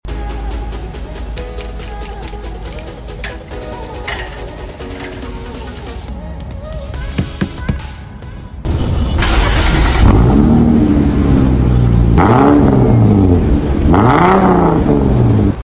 🚗✨ TURBO SOUND WHISTLE MUFFLER sound effects free download
🚗✨ TURBO SOUND WHISTLE MUFFLER EXHAUST